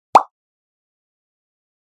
pop.mp3